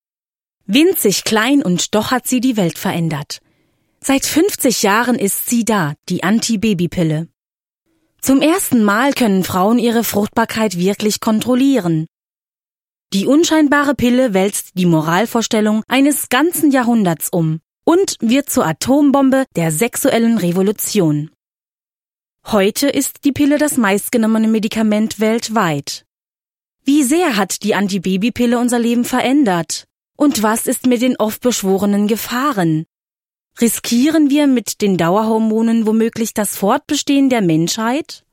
junge Stimme, variabel, dynamisch, sinnlich
Kein Dialekt
Sprechprobe: eLearning (Muttersprache):